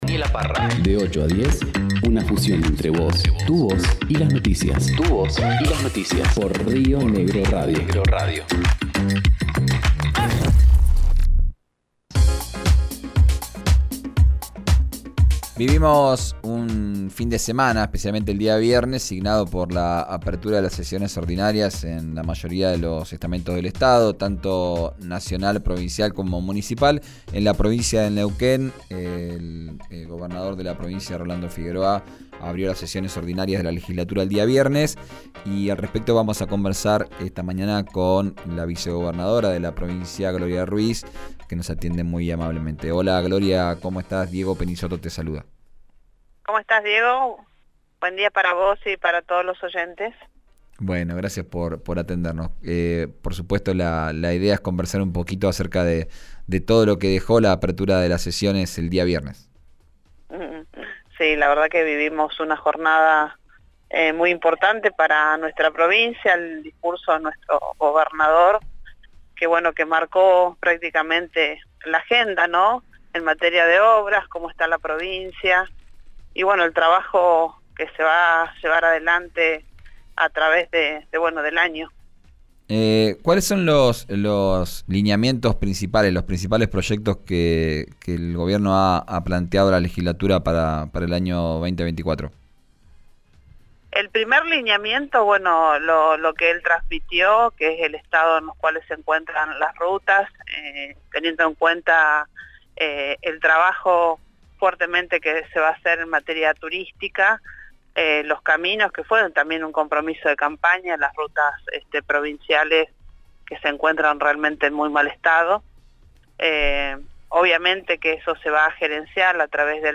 Escuchá a la vicegobernadora, Gloria Ruiz, en RÍO NEGRO RADIO: